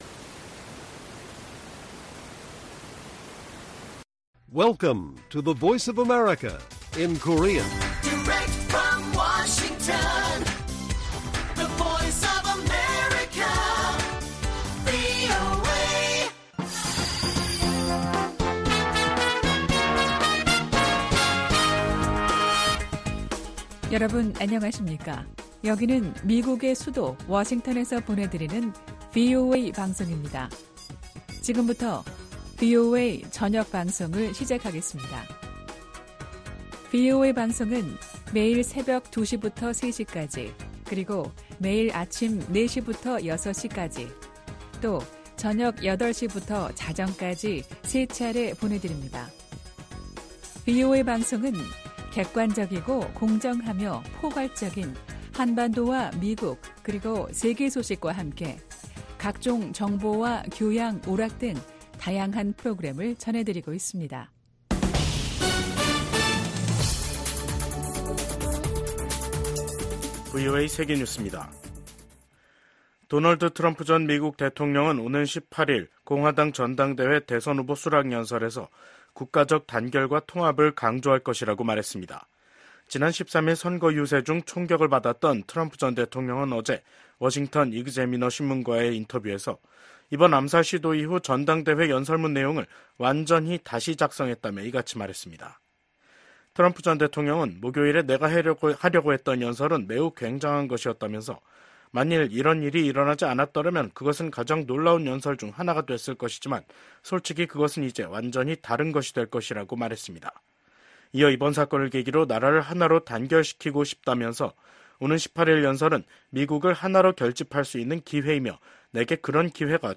VOA 한국어 간판 뉴스 프로그램 '뉴스 투데이', 2024년 7월 15일 1부 방송입니다. 조 바이든 미국 대통령은 트럼프 전 대통령 피격 사건이 나자 대국민 연설을 통해 폭력은 결코 해답이 될 수 없다고 강조했습니다. 도널드 트럼프 전 대통령에 대한 총격 사건과 관련해 미국 정치권과 각국 정상은 잇달아 성명을 내고 트럼프 전 대통령의 빠른 쾌유를 기원했습니다.